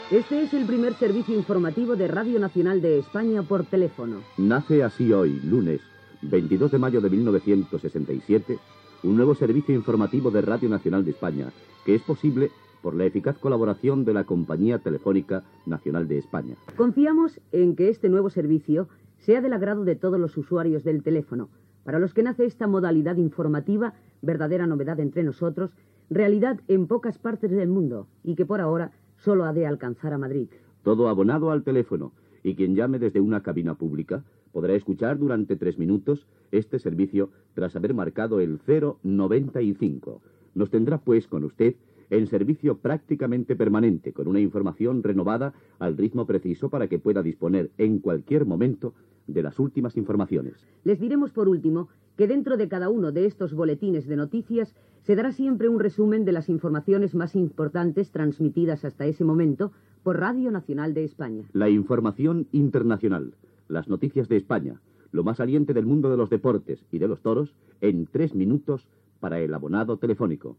Inauguració del Servicio informativo telefónico de Radio Nacional de España (Aquest contingut només es podia escoltar per telèfon).
Informatiu
Fragment extret del programa "'Amigos de la onda corta" de Radio Exterior (RNE) del 4 de gener de 2020.